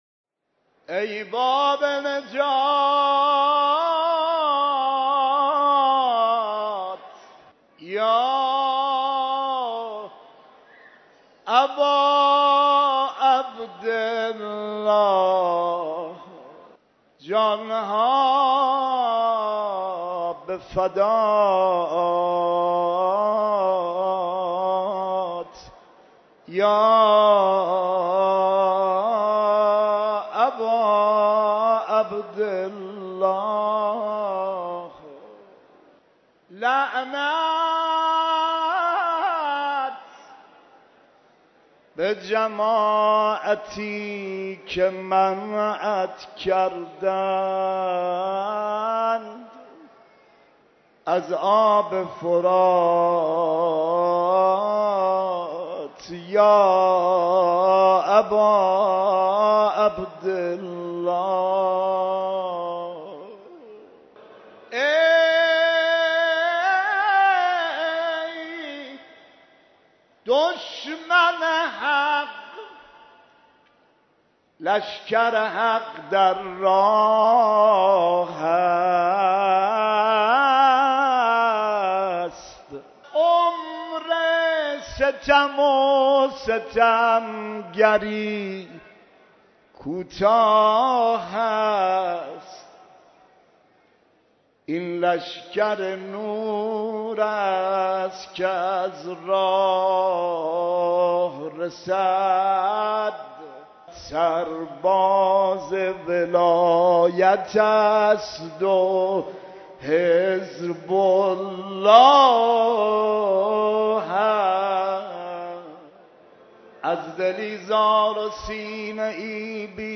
آخرین شب مراسم عزاداری حضرت اباعبدالله علیه‌السلام برگزار شد
مداحی